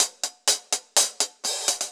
Index of /musicradar/ultimate-hihat-samples/125bpm
UHH_AcoustiHatC_125-04.wav